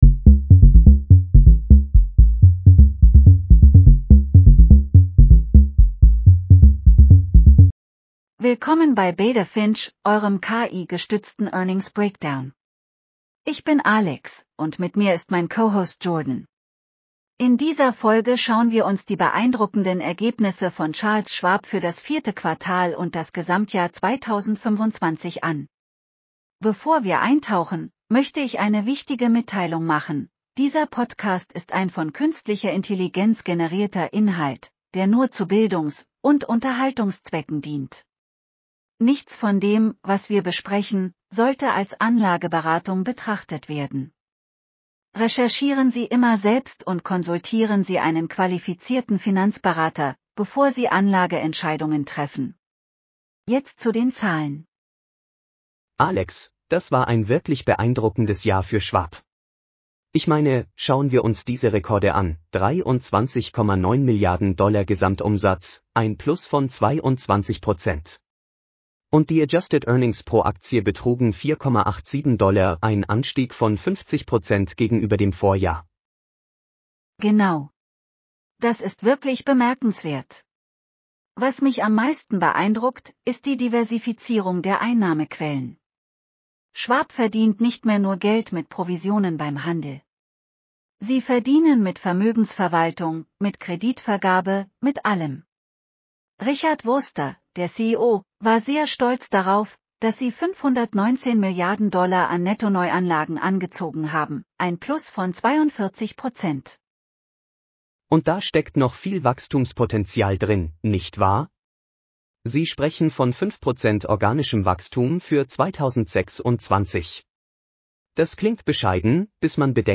Charles Schwab Q4 2025 earnings call breakdown.